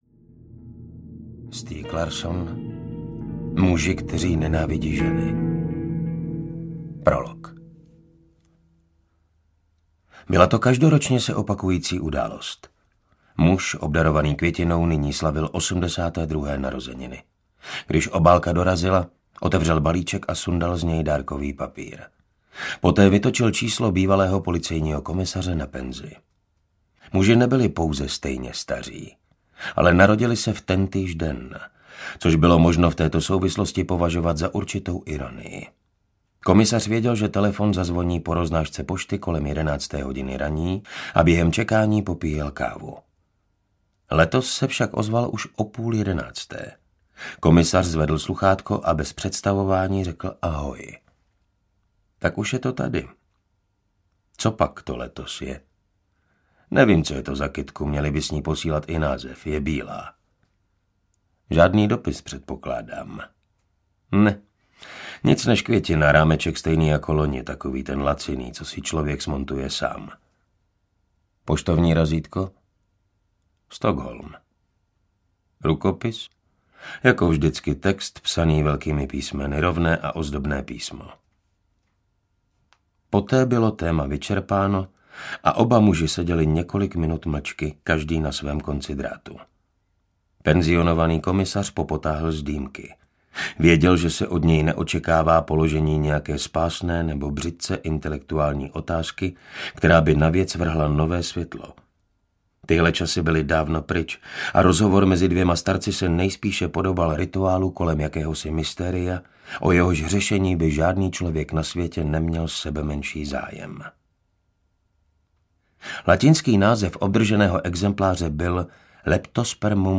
Milénium I.- III. – komplet audiokniha
Ukázka z knihy
• InterpretMartin Stránský